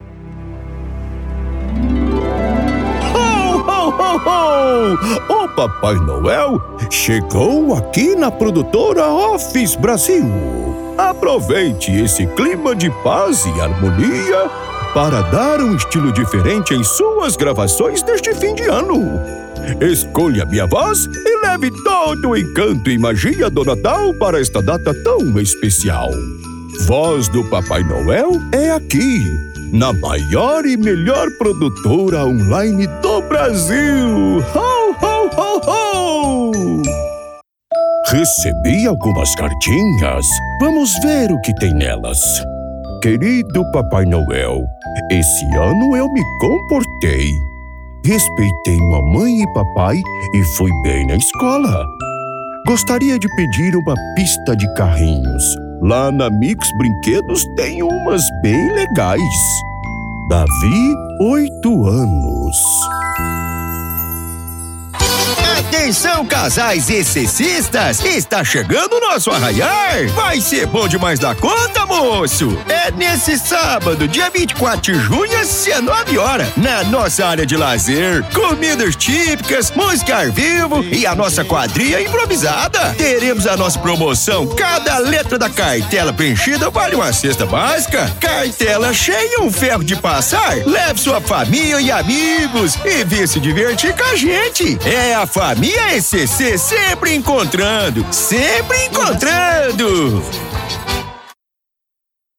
Demo Caricato: Papai Noel e Caipira: